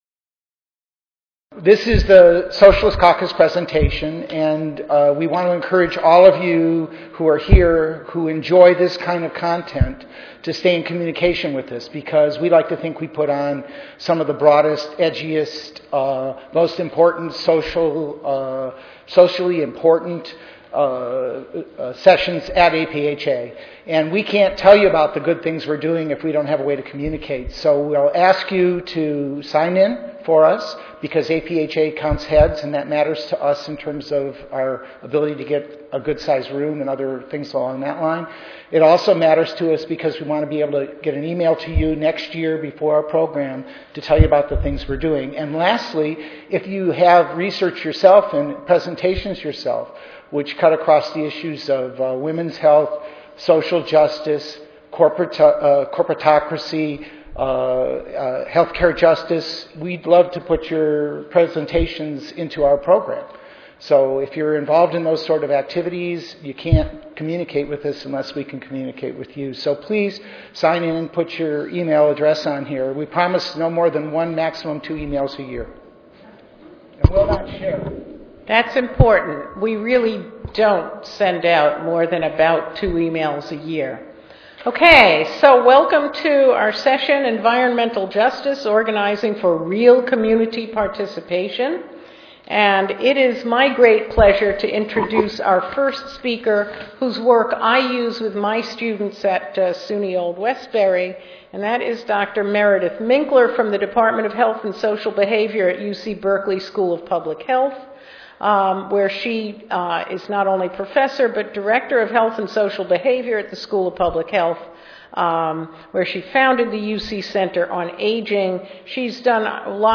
141st APHA Annual Meeting and Exposition (November 2 - November 6, 2013): Environmental Justice: Organizing for Real Community Participation